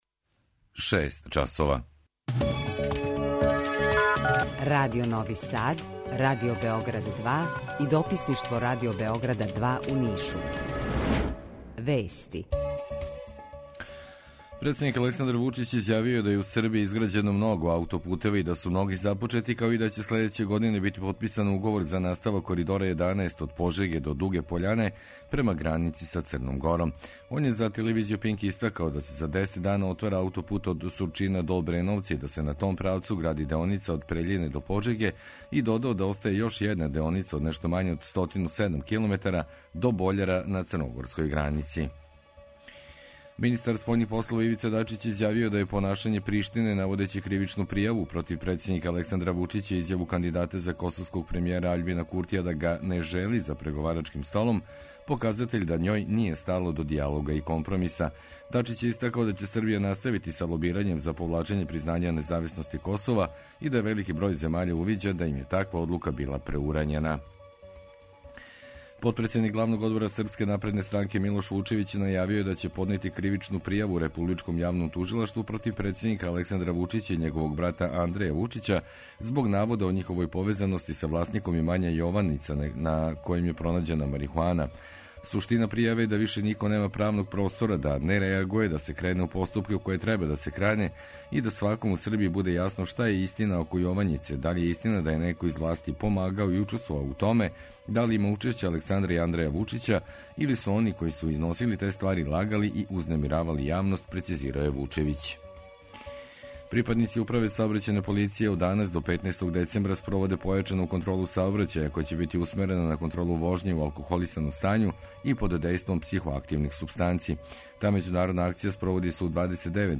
Jutarnji program zajednički realizuju Radio Beograd 2, Radio Novi Sad i dopisništvo Radio Beograda iz Niša.
U dva sata, tu je i dobra muzika, drugačija u odnosu na ostale radio-stanice.